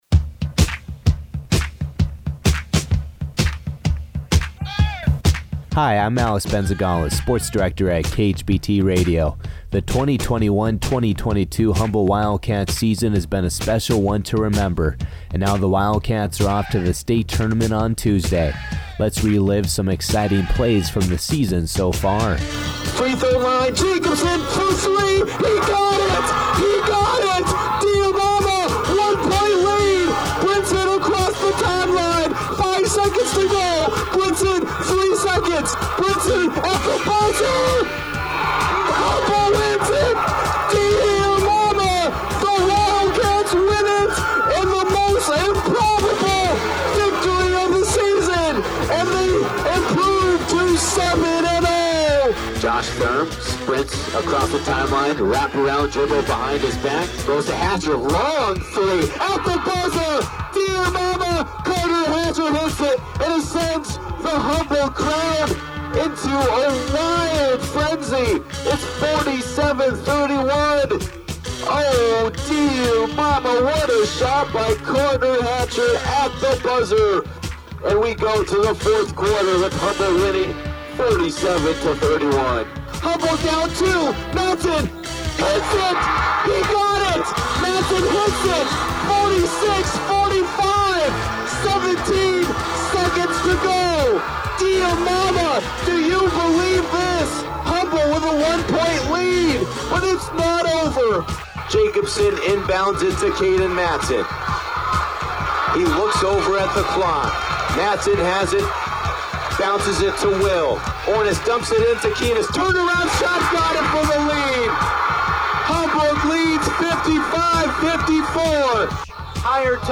8068_KHBT_Wildcat-Highlight-with-Music.mp3